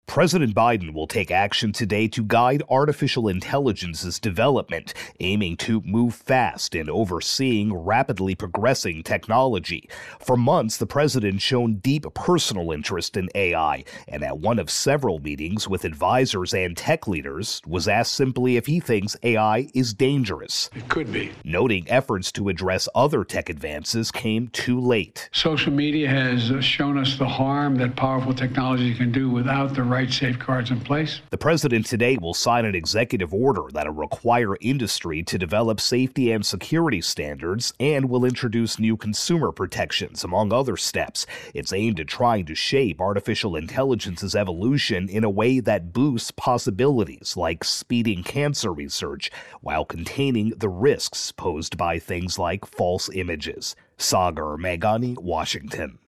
AP Washington correspondent